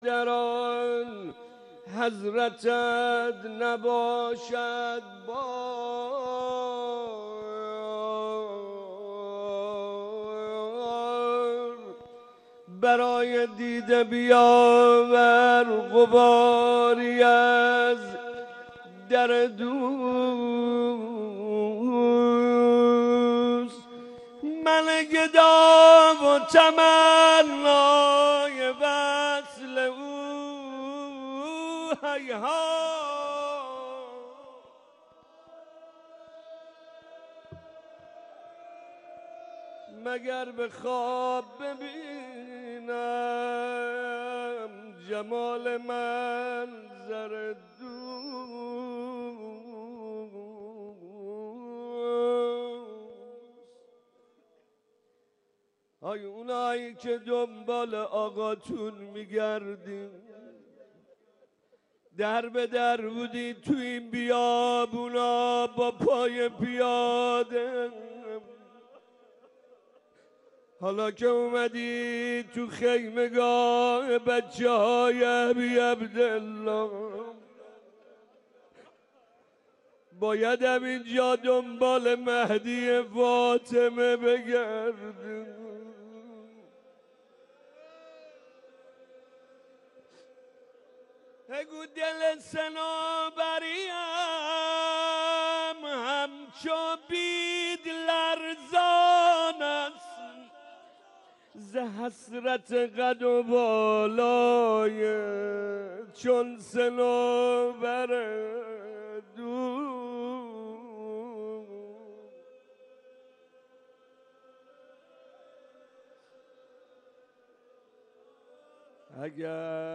مناجات امام زمان
روضه